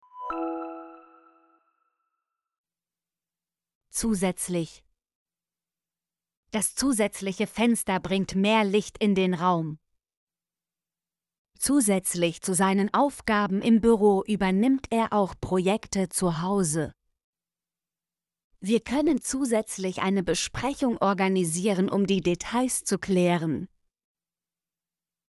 zusätzlich - Example Sentences & Pronunciation, German Frequency List